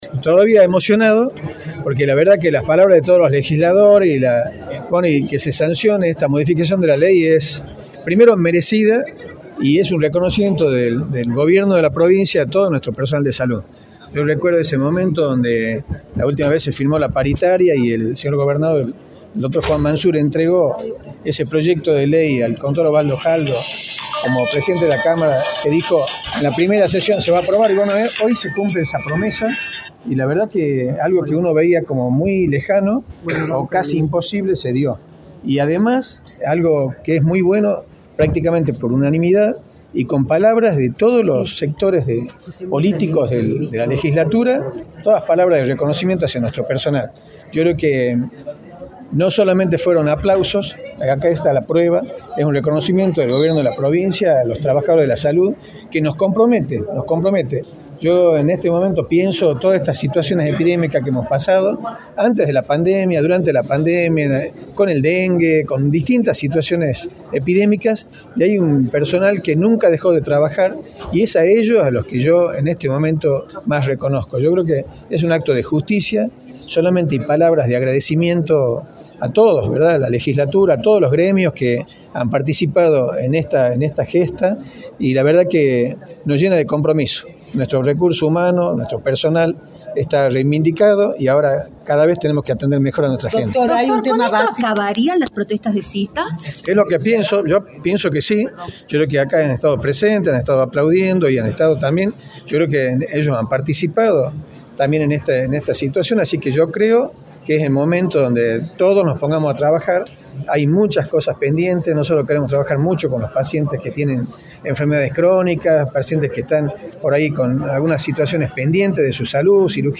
“Esto es un reconocimiento del Gobierno de Tucumán para los trabajadores de la salud” señaló el Dr, Medina Ruiz en entrevista para Radio del Plata Tucumán, por la 93.9.